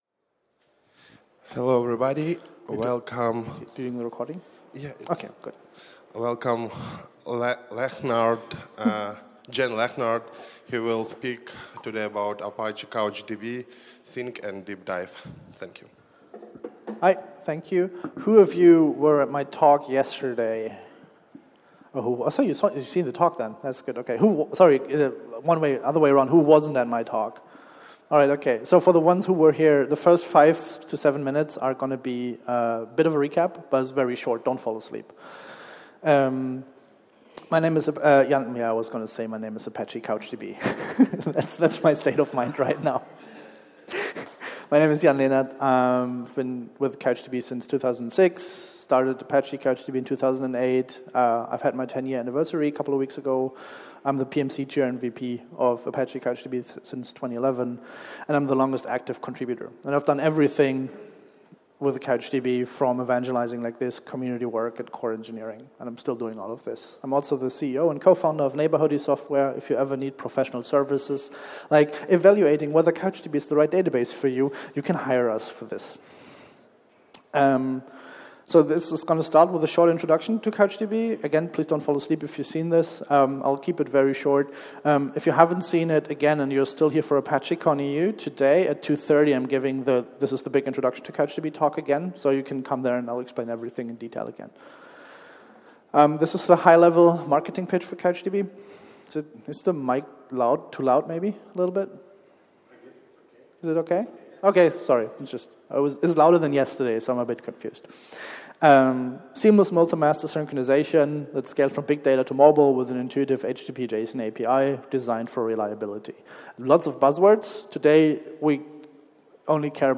Apache Big Data Seville 2016